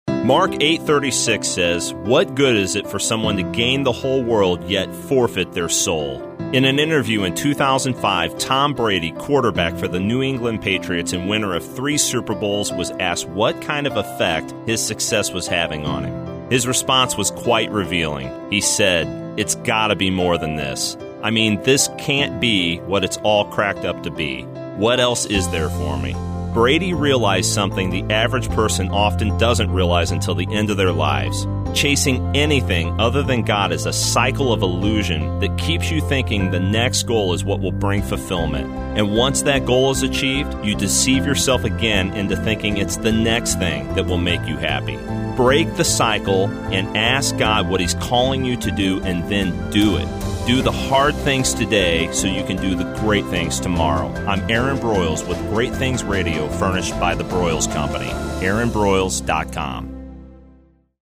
I’m excited to introduce Great Things Radio (1 min motivational messages) airing now on Bott Radio Network on 91.5 FM in St. Louis at approximately 5:35 p.m. CST (top of the second break in the Bible Answer Man Hank Hanegraaff broadcast).